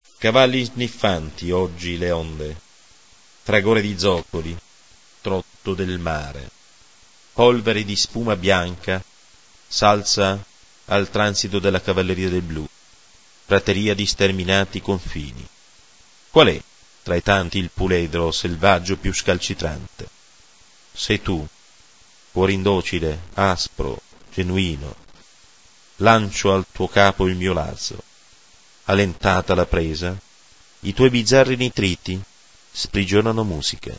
POESIA SONORA IN mp3.